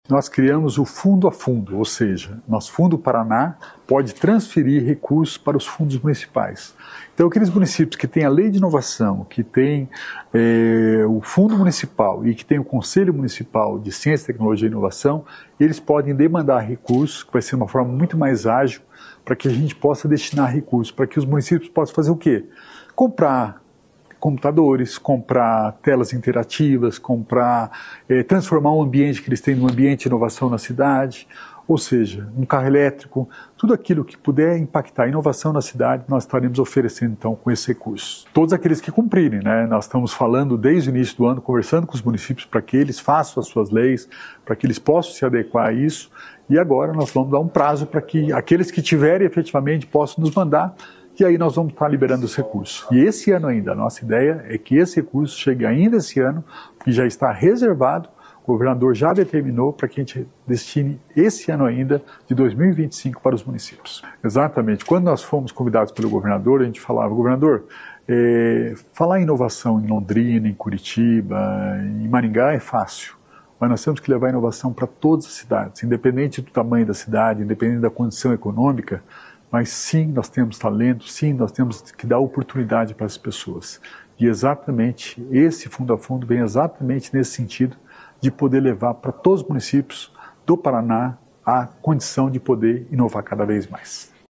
Sonora do secretário da Inovação e Inteligência Artificial, Alex Canziani, sobre o Pacto da Inovação